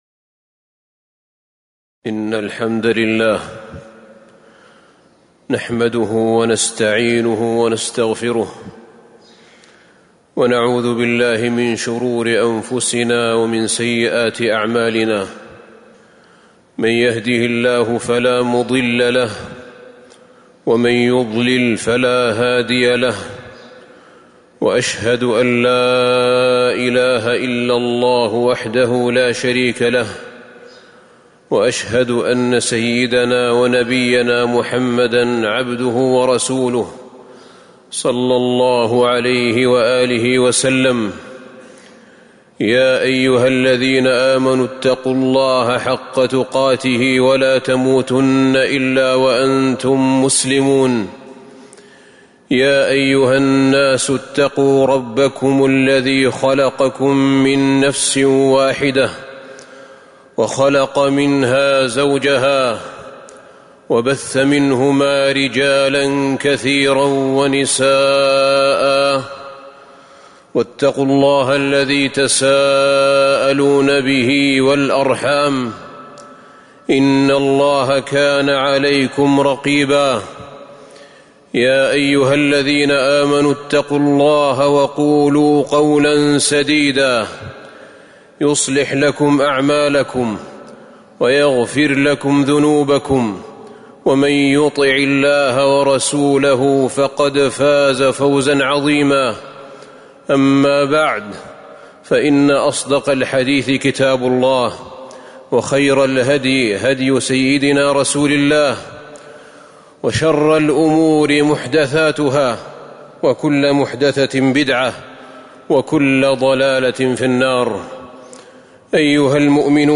تاريخ النشر ٢٤ رجب ١٤٤٣ هـ المكان: المسجد النبوي الشيخ: فضيلة الشيخ أحمد بن طالب بن حميد فضيلة الشيخ أحمد بن طالب بن حميد اعتلال القلوب